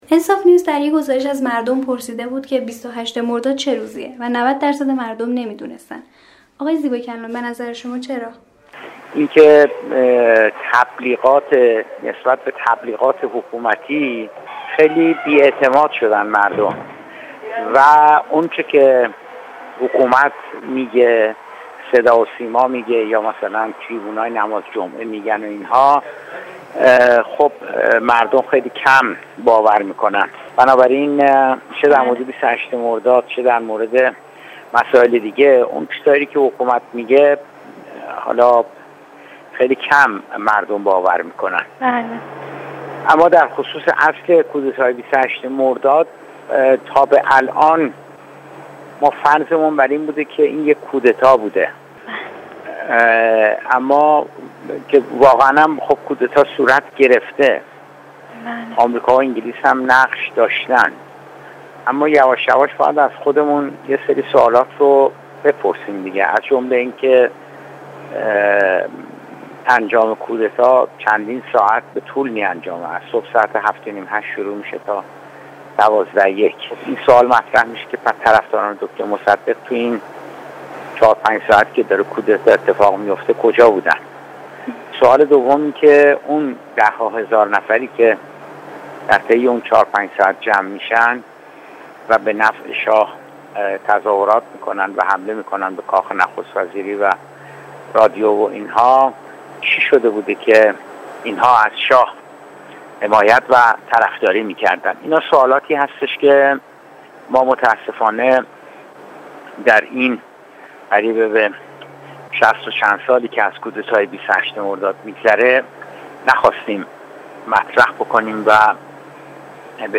زیباکلام در گفت‌وگو با خبرنگار انصاف نیوز درباره ی کودتای 28 مرداد گفت: تا به حال فرض کرده‌ایم که 28 مرداد کودتا بوده است.